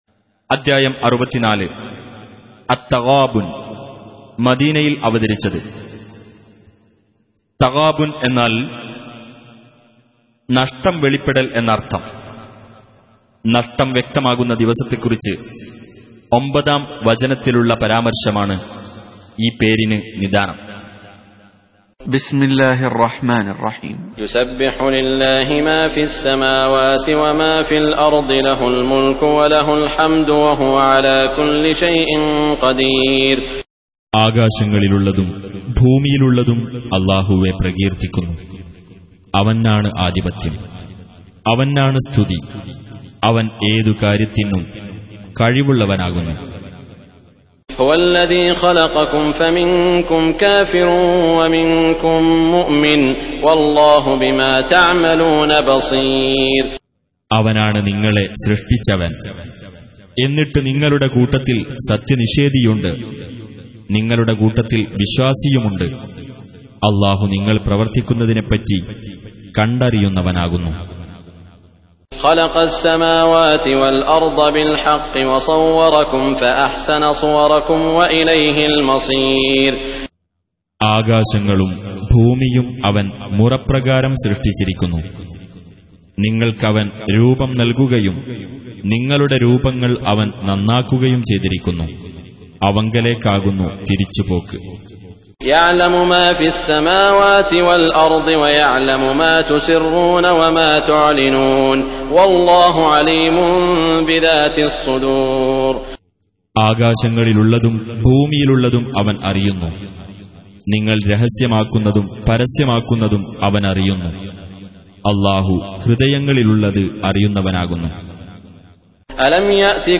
Audio Quran Tarjuman Translation Recitation